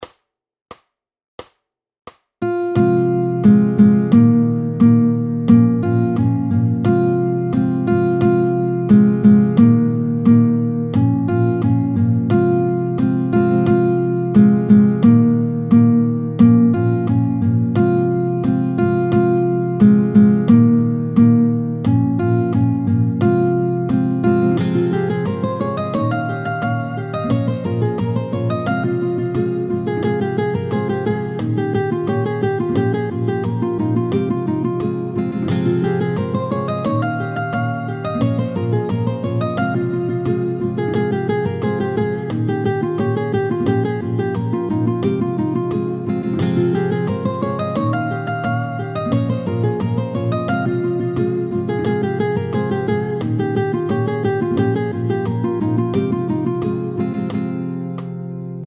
La chanson est donc construite en SOL majeur.
tempo 88